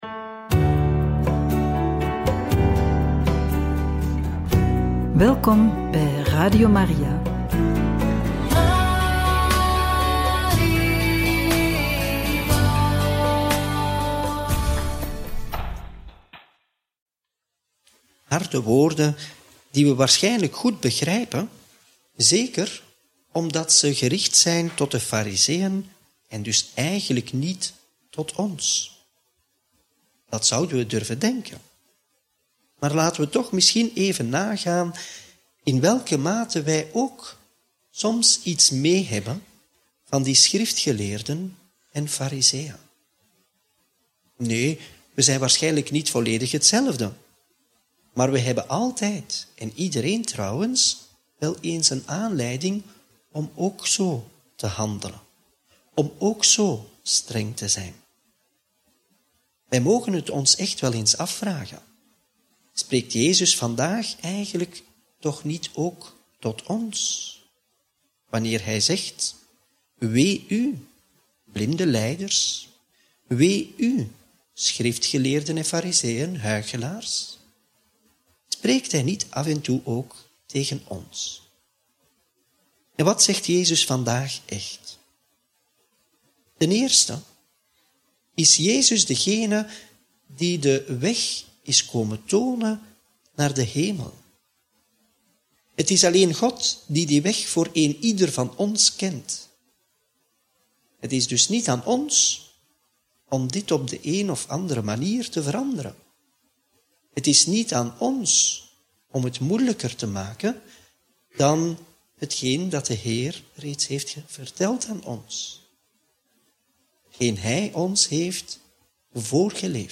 Homilie bij het Evangelie op maandag 26 augustus 2024 – Mt. 23, 13-22